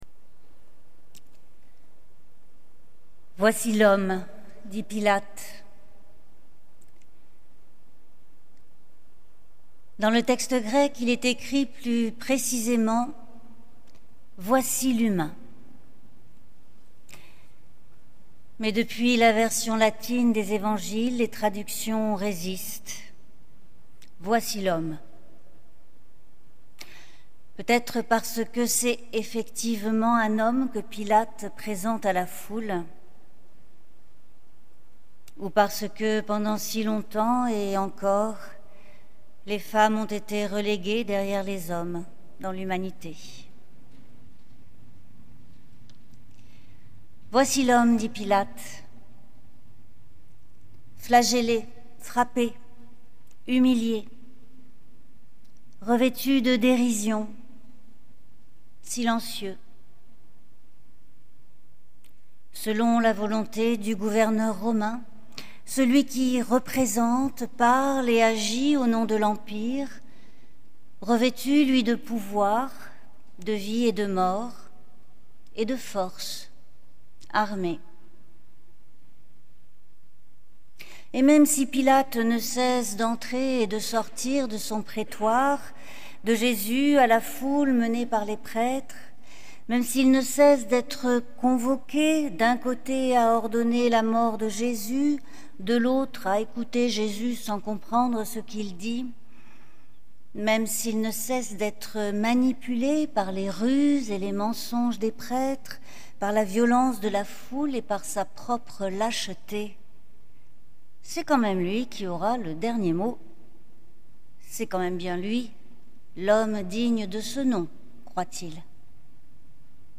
Lecture musicale et méditative de la Passion selon Jean